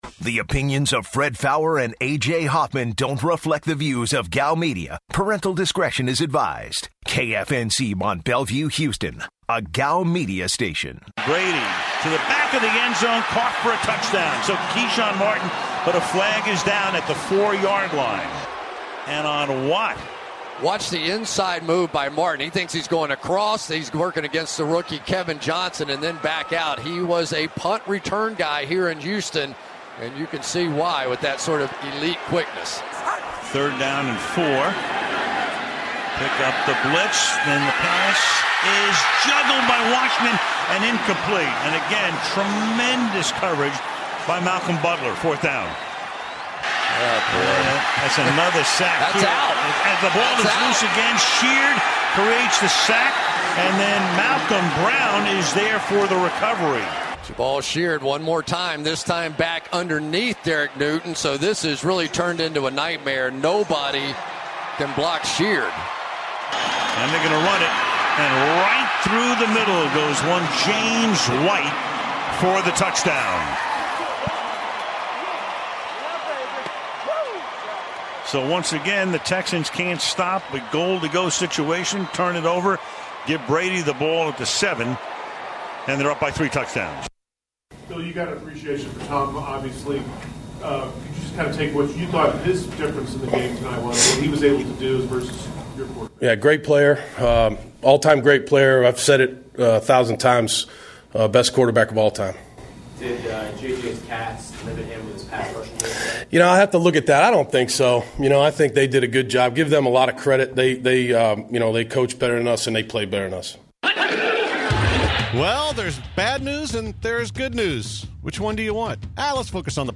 To open the show, the guys react to the Texans loss to the Patriots on Sunday Night Football. Plus, which is the worst division in the NFL? The guys, also, take calls from Blitzers regarding Week 14 of the NFL.